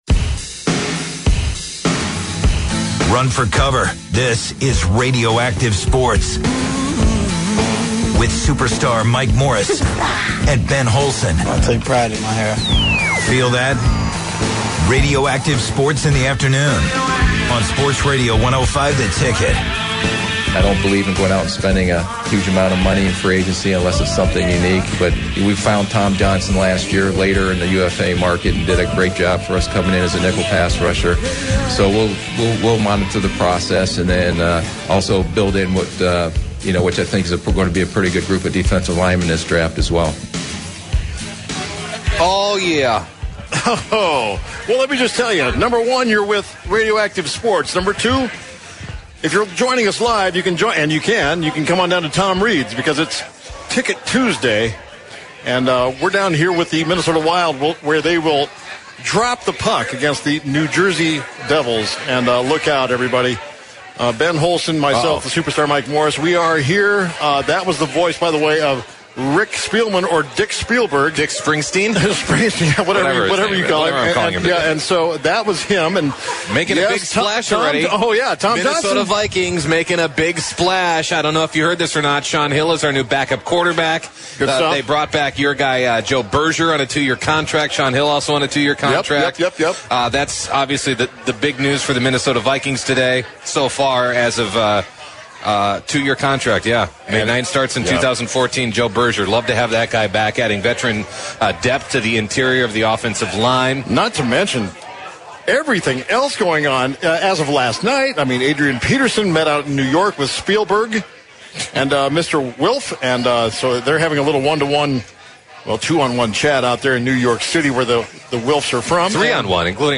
Radioactive Sports live from Tom Reid's Pub in Saint Paul is kicked off with NFL free agency and the guys's opinions on what's next for the Vikings. NHL Legend Tom Reid joins to talk about the latest for the Minnesota Wild and to preview their game against the New Jersey Devils.